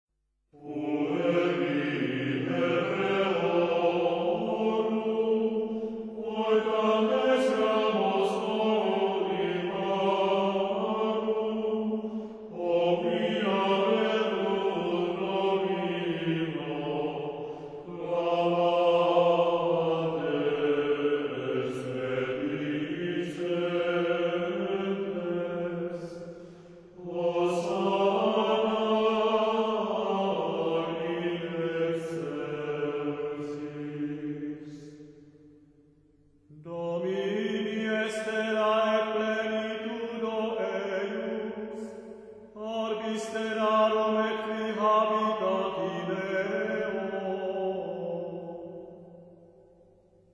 Григорианское пение: сборники
Boni Puncti – Gregorian Chants
Очень красивое и величественное звучание.